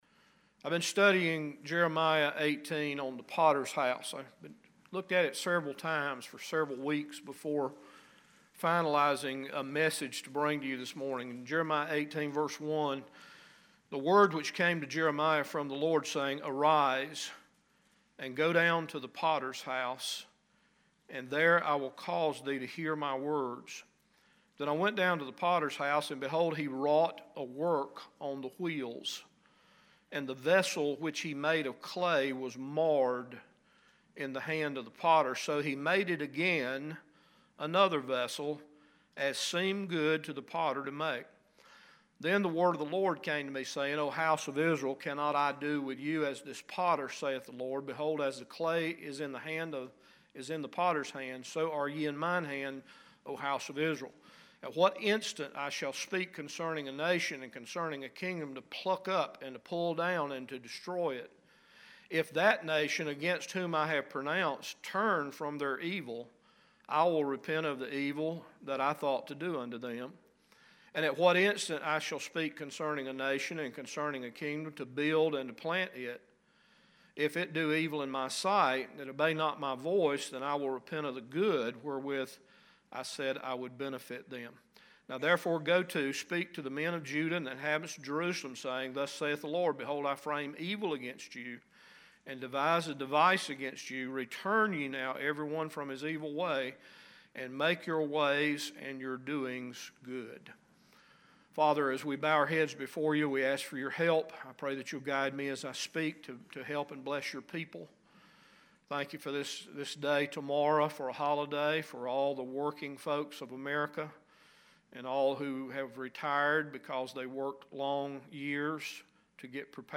9-2-18-AM-Mesage.mp3